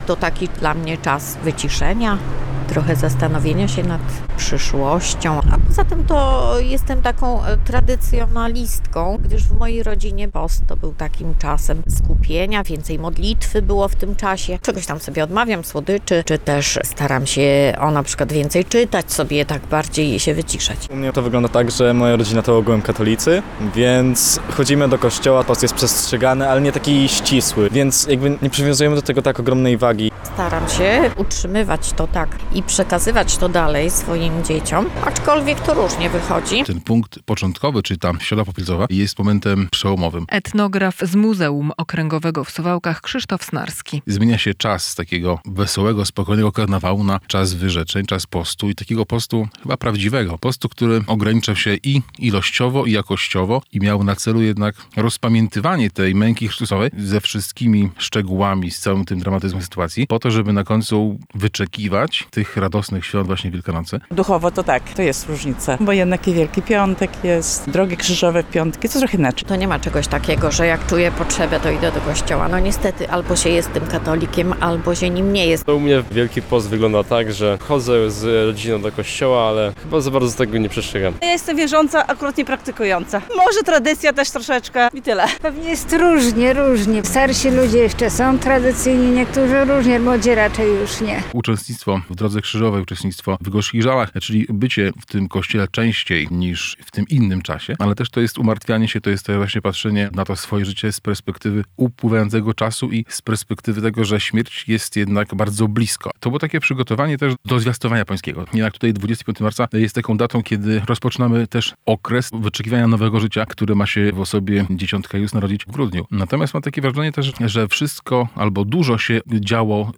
Środa Popielcowa - początek Wielkiego Postu w kościele katolickim - relacja